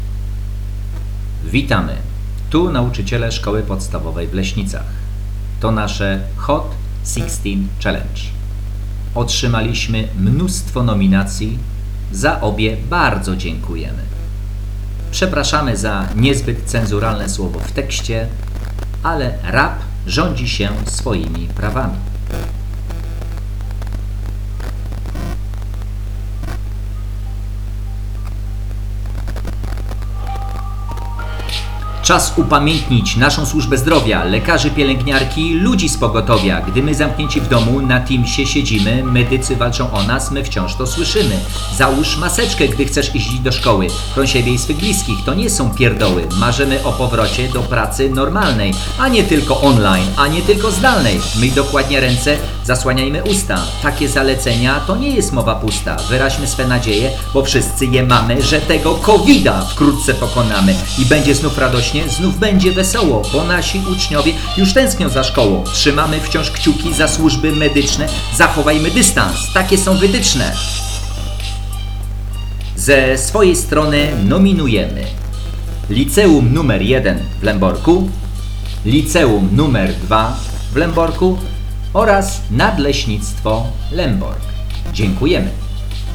A oto nasze, Szkoły Podstawowej w Leśnicach, naprędce sklecone tłuste bity:
Przepraszamy za niezbyt cenzuralne słowo w tekście, ale rap rządzi się swoimi prawami :)